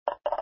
emptycup.mp3